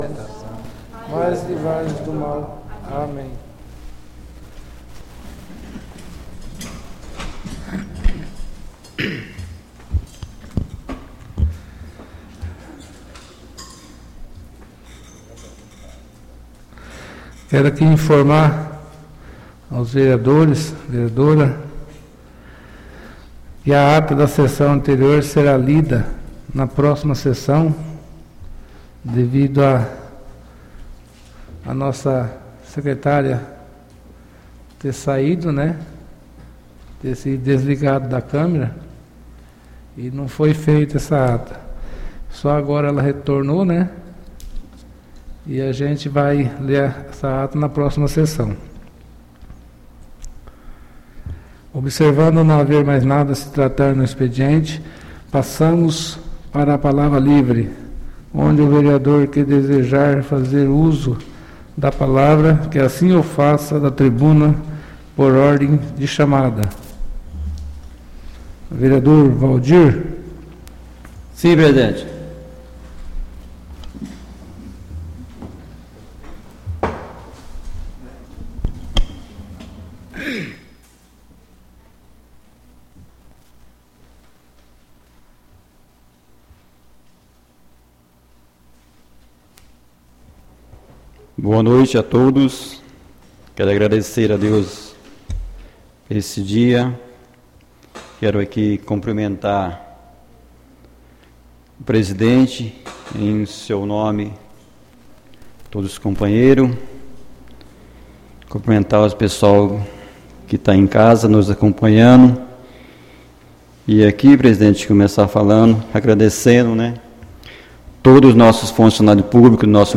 ÁUDIO SESSÃO 02-03-21 — CÂMARA MUNICIPAL DE NOVA SANTA HELENA - MT